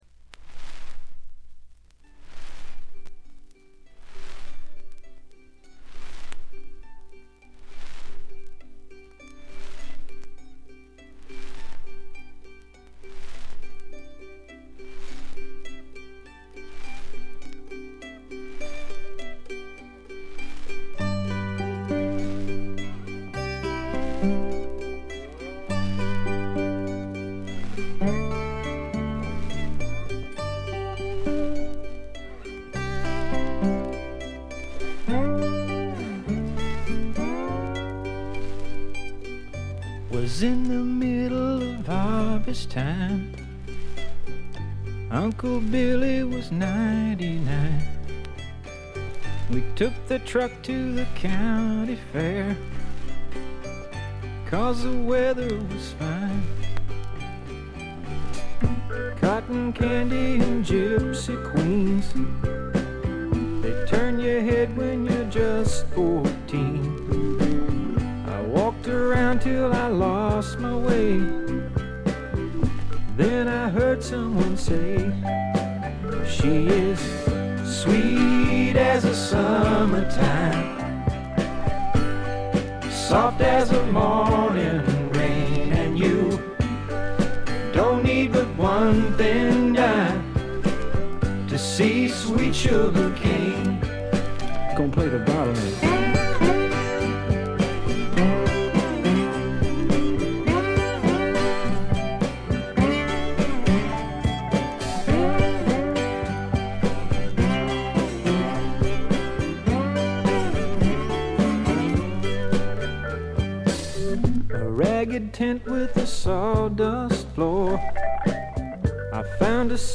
両面とも1曲目から2曲目にかけて「ザー」という周回ノイズ。これ以外は軽微なチリプチ程度です。
本来カントリー畑のソングライターですが本作では曲が良いのはもちろん渋いヴォーカルも披露しております。
試聴曲は現品からの取り込み音源です。
Acoustic Guitar, Banjo, Drums, Guitar [Bottleneck]
Mandolin